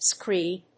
/skríːd(米国英語), skri:d(英国英語)/